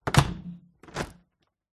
На этой странице собраны реалистичные звуки пластиковых дверей: от плавного открывания до резкого захлопывания.
Звук закрывающейся пластиковой двери на балконе или в комнате